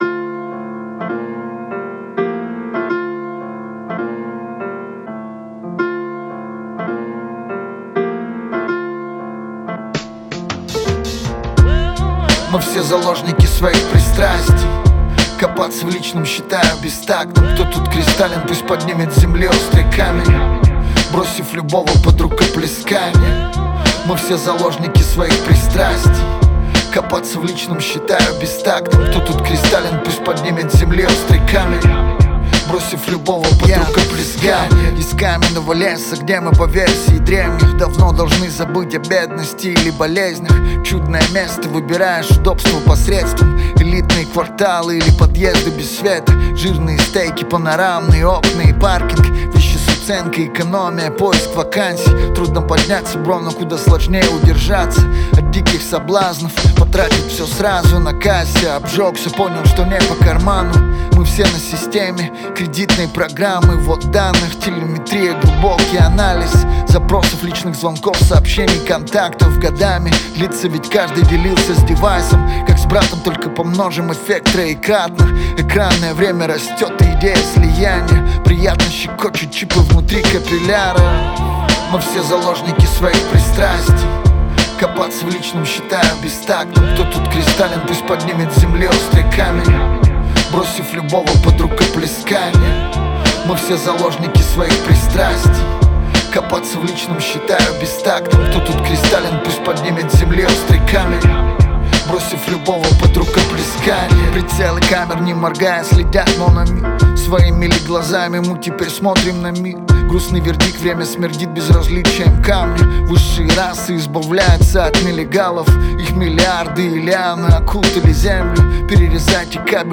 который сочетает в себе элементы хип-хопа и рок-музыки.